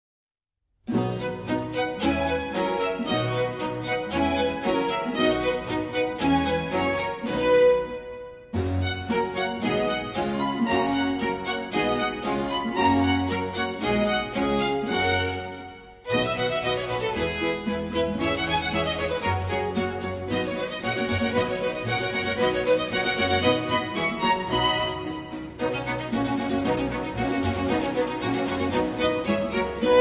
• Registrazione sonora musicale